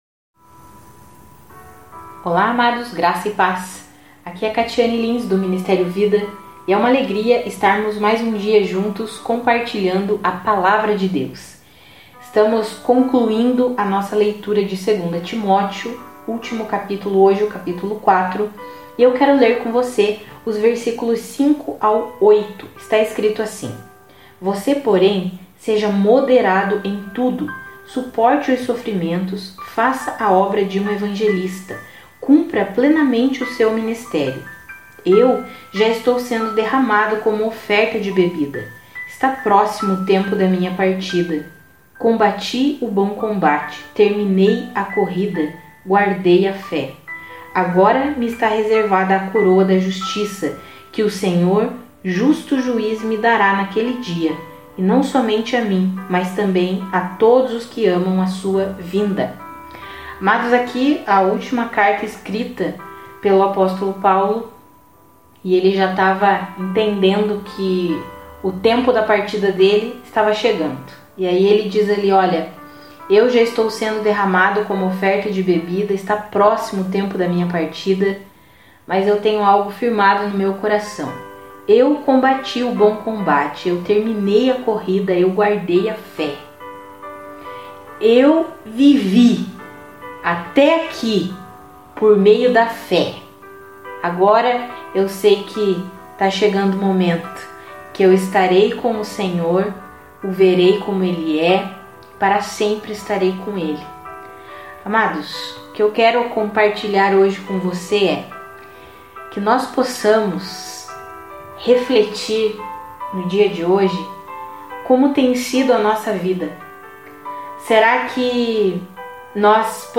Além da leitura Bíblica nós fornecemos um devocional escrito e um devocional em áudio, todos os dias, para edificar a sua fé.